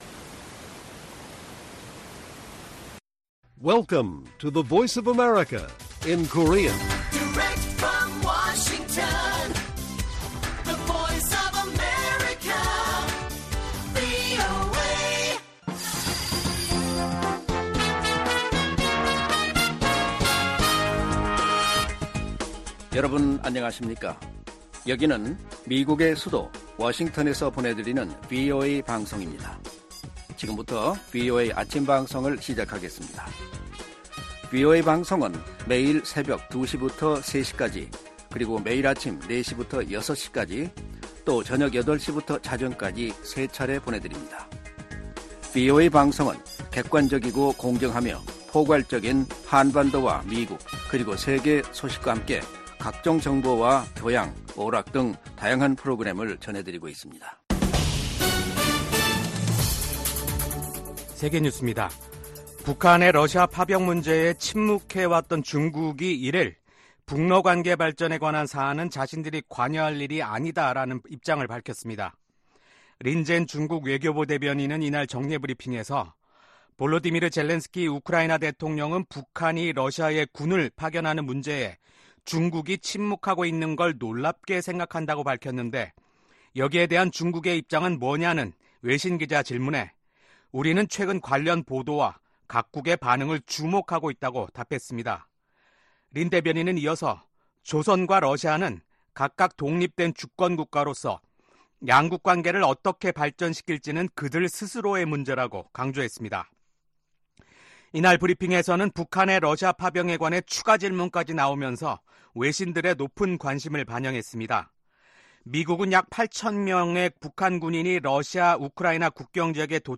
세계 뉴스와 함께 미국의 모든 것을 소개하는 '생방송 여기는 워싱턴입니다', 2024년 11월 2일 아침 방송입니다. 미국 대선에서 선거일 전에 투표하는 사람의 비율이 50%를 넘을 것으로 전망된다고 여론조사 업체인 갤럽이 발표했습니다. 미국 정부 고위 관리들이 베냐민 네타냐후 이스라엘 총리를 만나 헤즈볼라와의 휴전 문제를 논의했습니다.